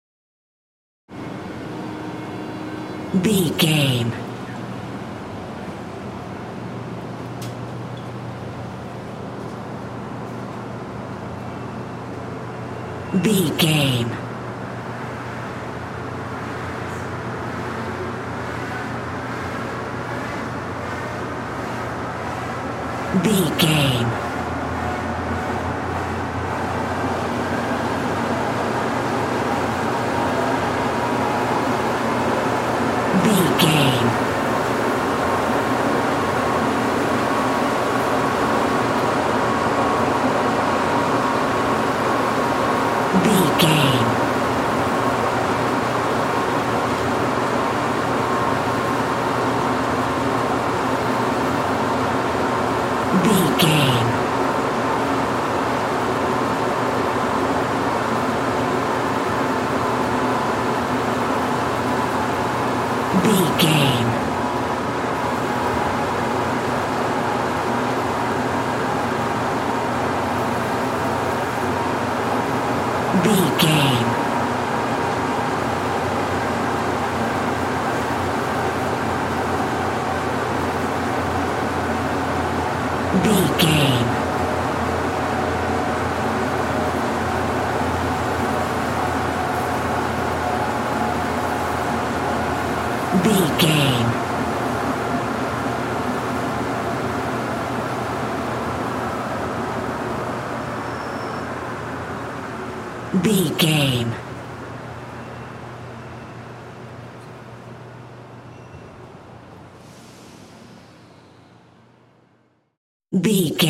Subway int train leave arrive station
Sound Effects
urban
ambience